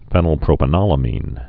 (fĕnəl-prōpə-nŏlə-mēn, fēnəl-)